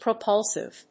音節pro・pul・sive 発音記号・読み方
/prəpˈʌlsɪv(米国英語)/